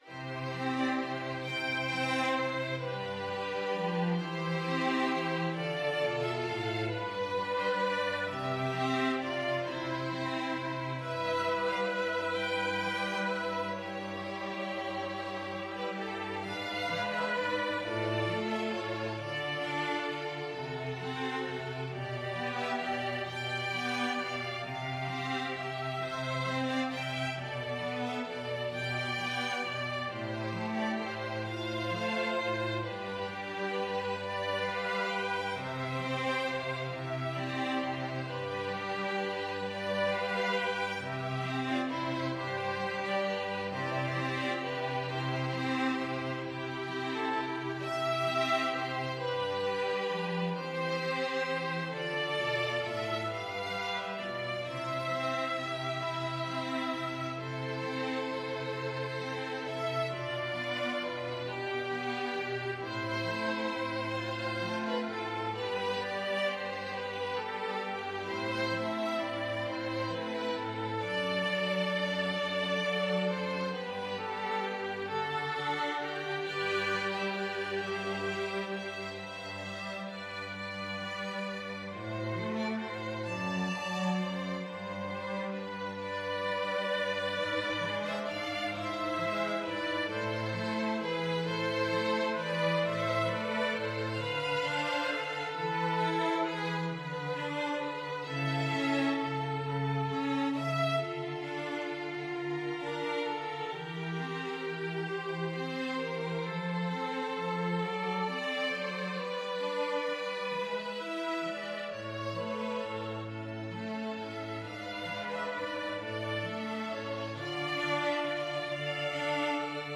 Violin 1Violin 2ViolaCello
12/8 (View more 12/8 Music)
. = 44 Largo
Classical (View more Classical String Quartet Music)